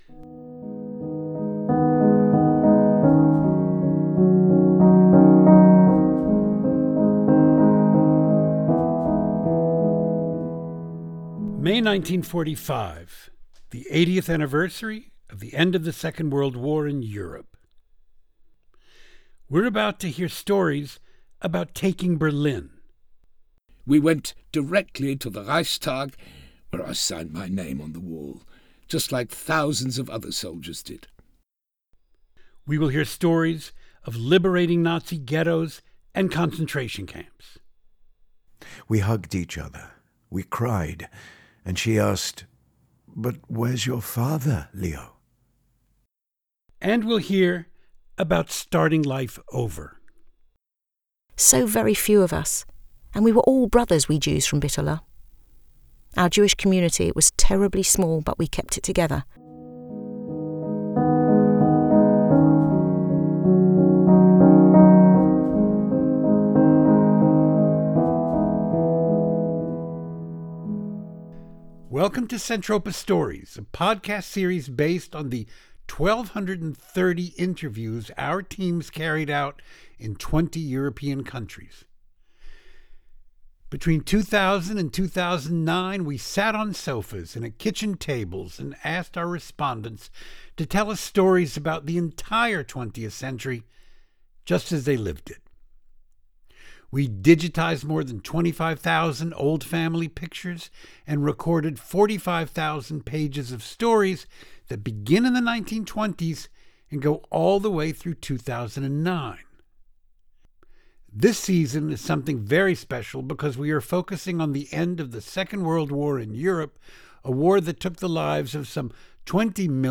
We have translated and edited them and they are read for us by actors in London.